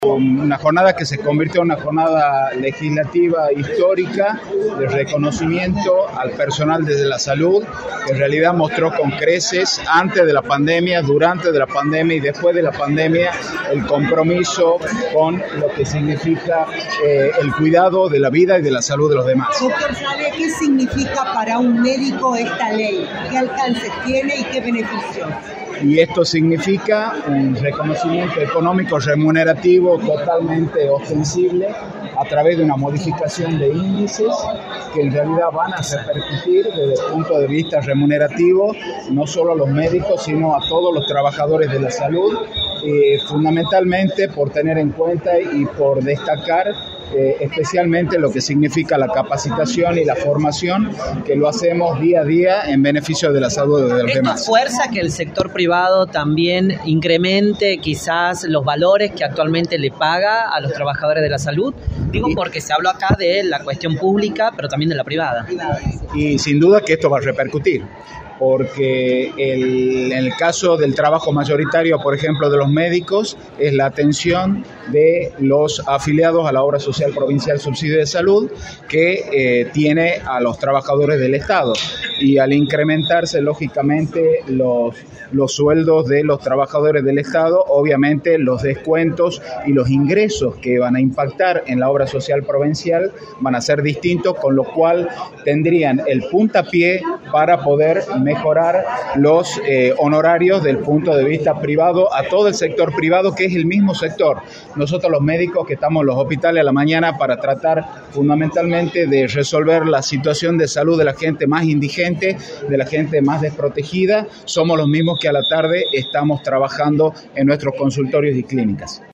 afirmó en entrevista para Radio del Plata Tucumán